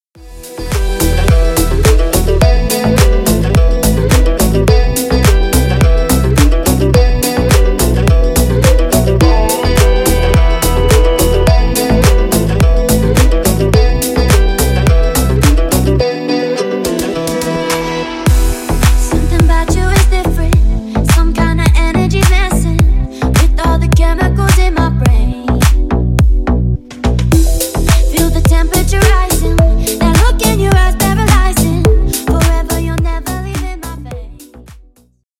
Танцевальные
восточные